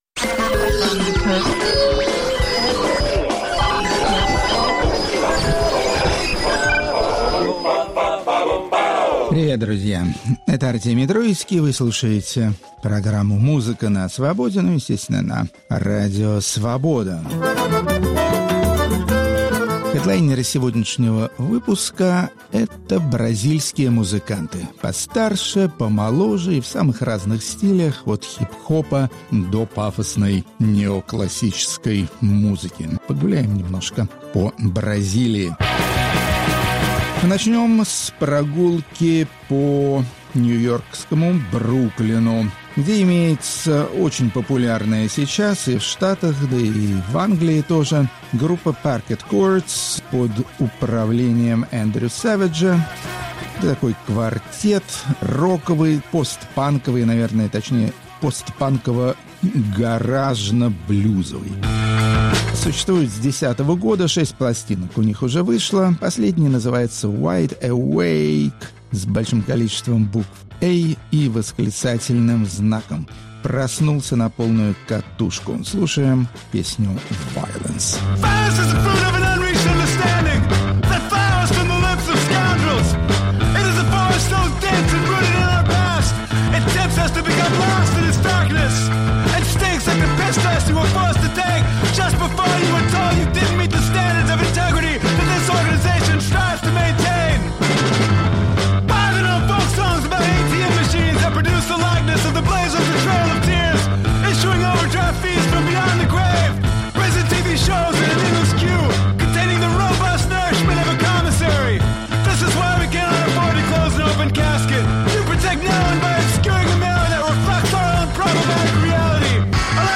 Исполнители популярных мелодий и песен из Бразилии, а эта страна считается одним из лидеров мировой этнической музыки. Рок-критик Артемий Троицкий знакомит нас с мастерами искусств разных жанров, все жанры отличаются друг от друга, а все исполнители отличаются талантом.